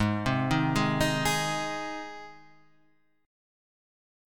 Ab chord